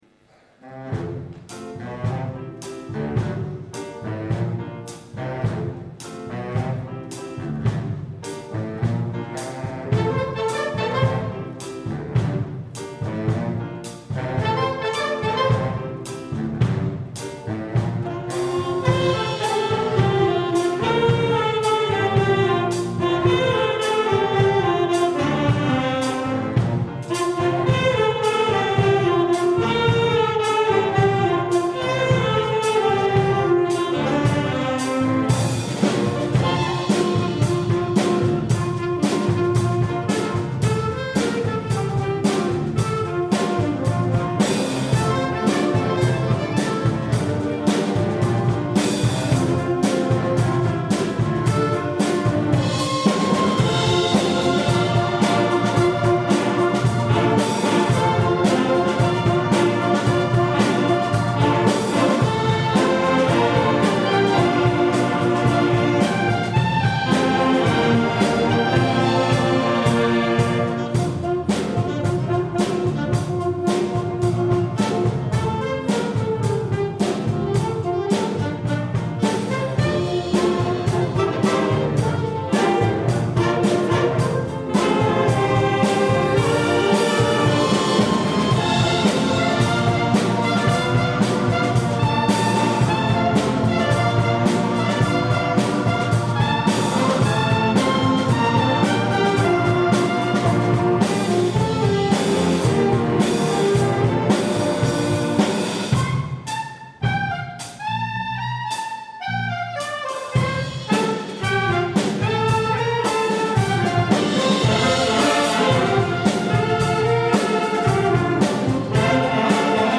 Massed Saxes